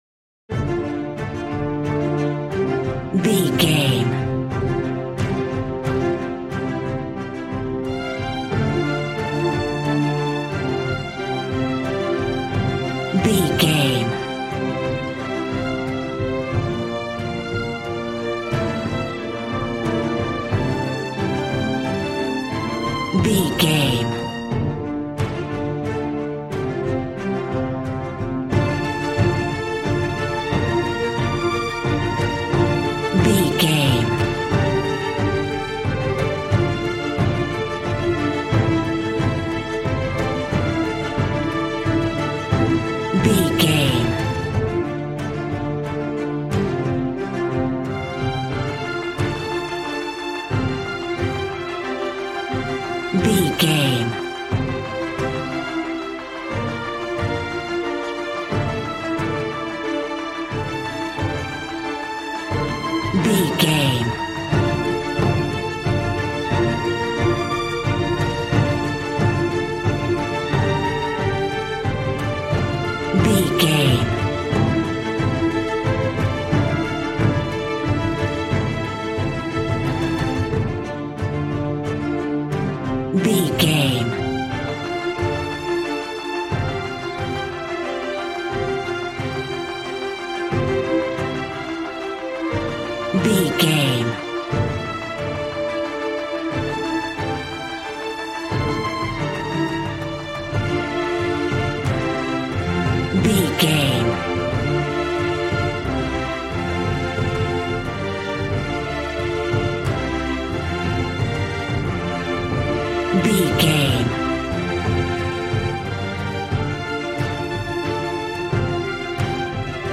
Aeolian/Minor
suspense
piano
synthesiser